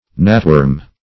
Search Result for " gnatworm" : The Collaborative International Dictionary of English v.0.48: Gnatworm \Gnat"worm`\, n. (Zool.) The aquatic larva of a gnat; -- called also, colloquially, wiggler .